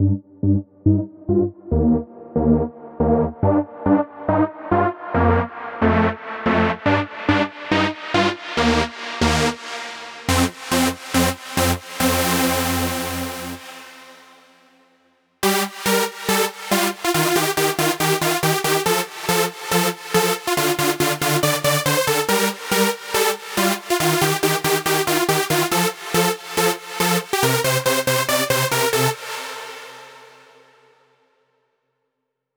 VTDS2 Song Kit 12 Male One Second Synth Lead.wav